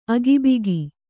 ♪ agibigi